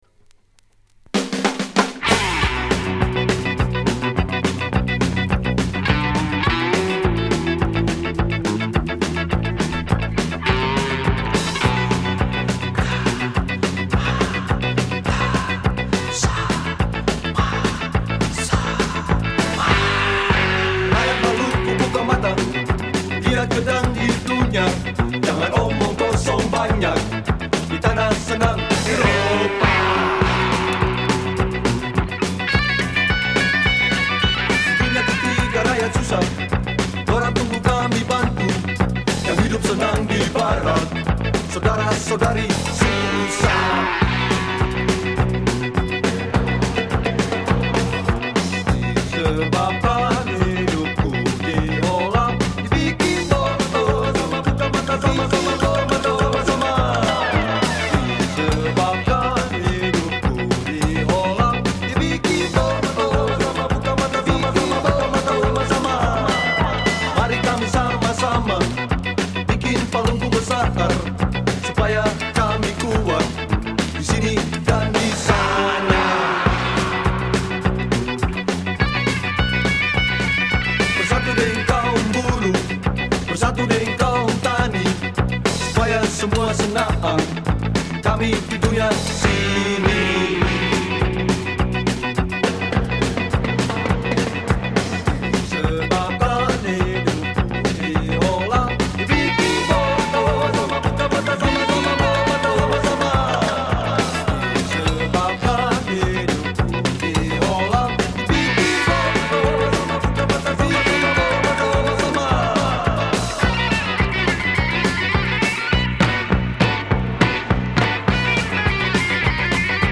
Drums
Slaggitaar
Keyboards/Sologitaar
Vocals
Percussie